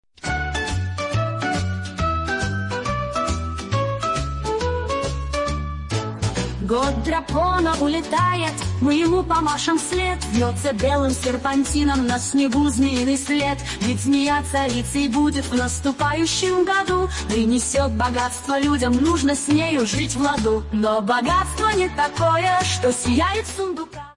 Фрагмент 2 варианта исполнения: